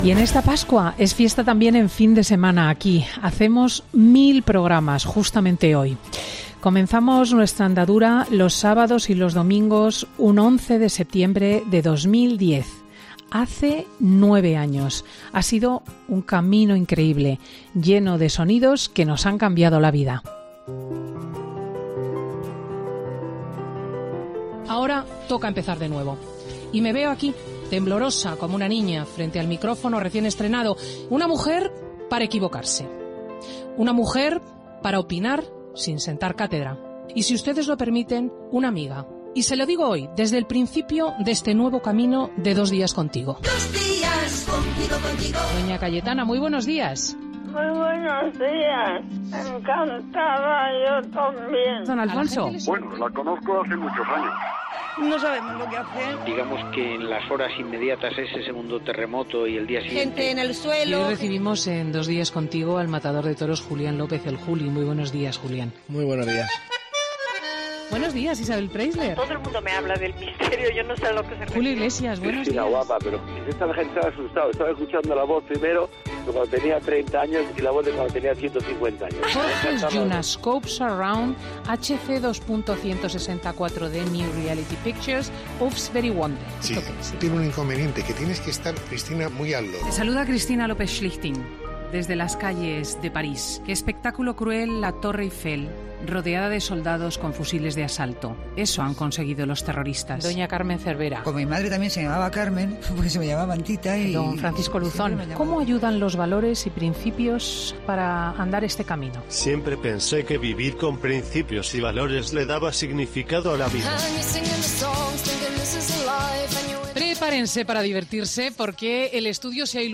Recordamos los inicios y las mejores entrevistas de la presentadora de los fines de semana de COPE